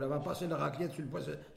Saint-Christophe-du-Ligneron
Locution